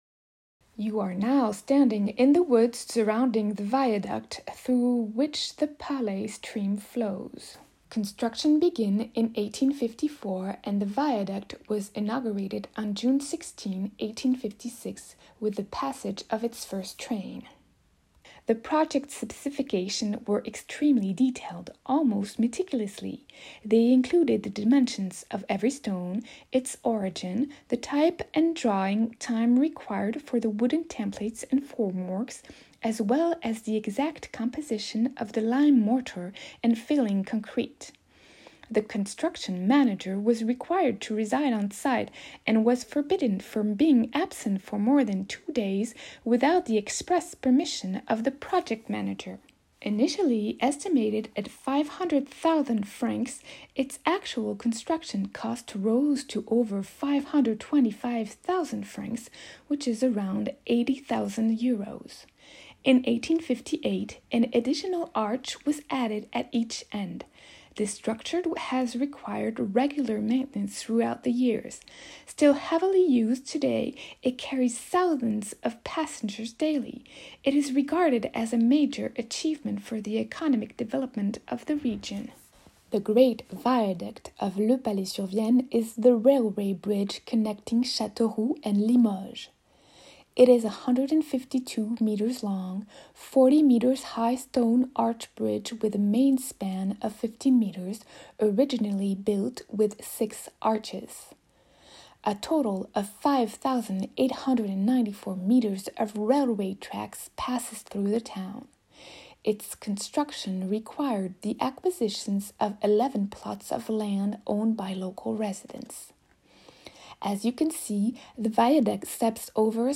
grand-viaduc_version-anglaise.mp3